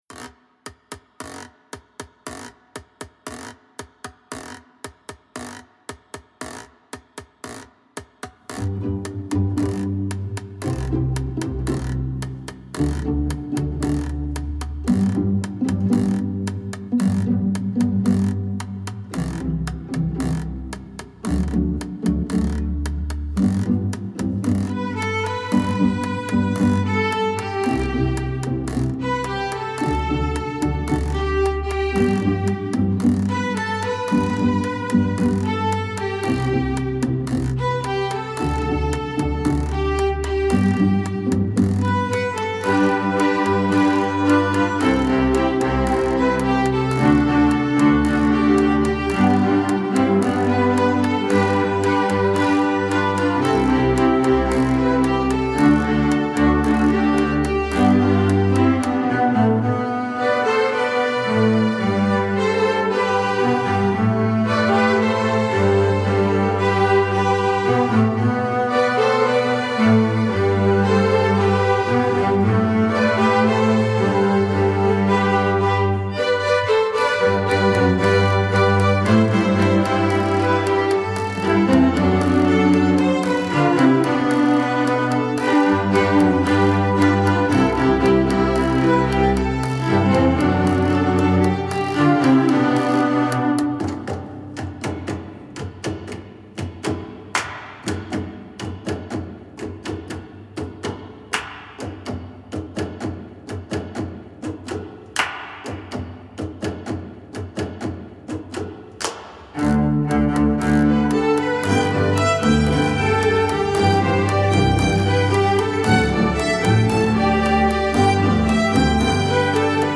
Composer: South African Folk Song
Voicing: String Orchestra